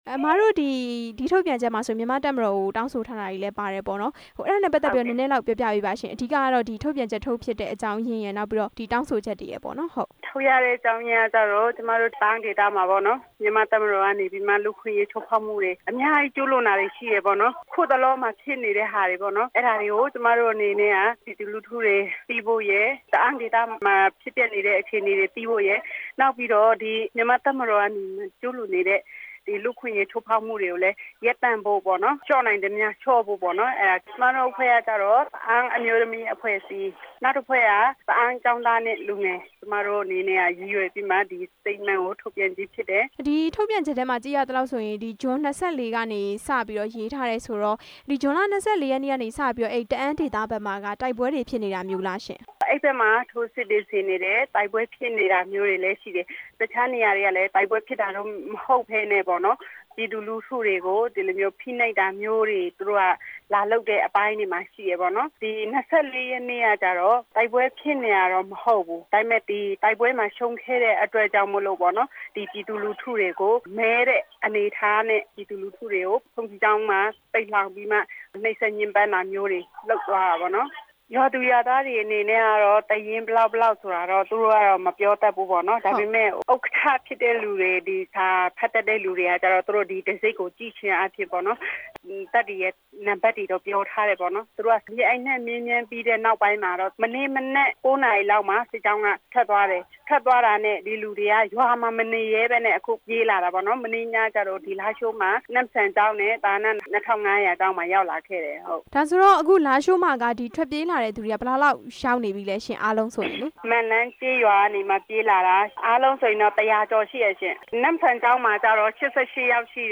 တအာန်းဒေသ စစ်ရေးအခြေအနေ မေးမြန်းချက်